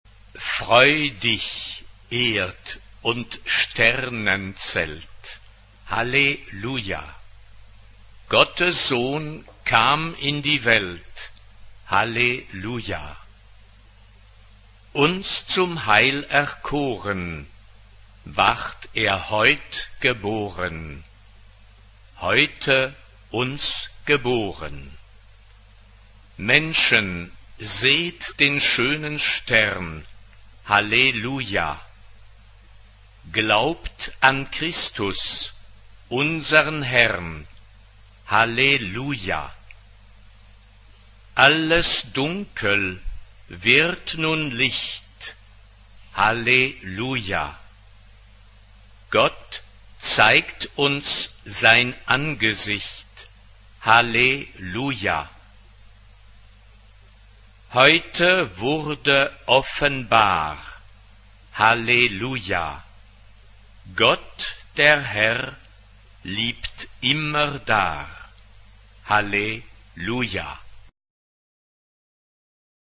SSA (3 voices women) ; Full score.
Tonality: G major